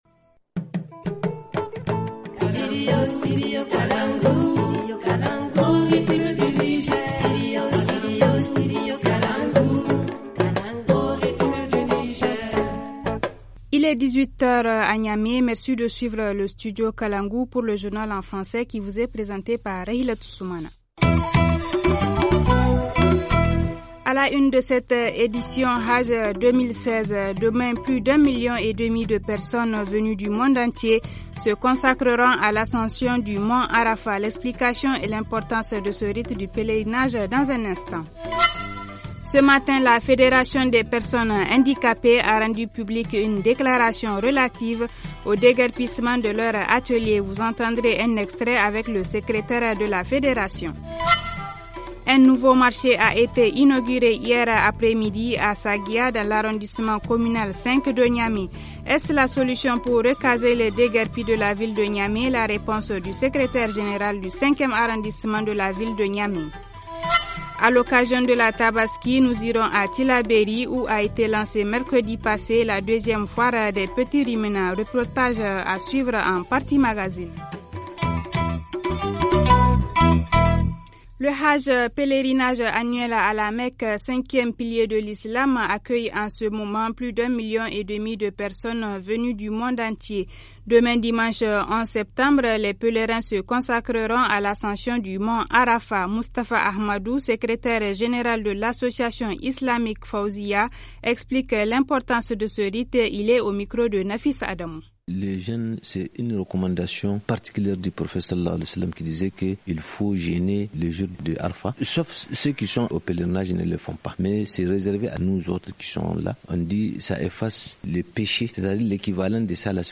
-Ce matin la Fédération des personnes handicapées publié une déclaration relative au déguerpissement de leurs ateliers. Vous en entendrez un extrait dans ce journal.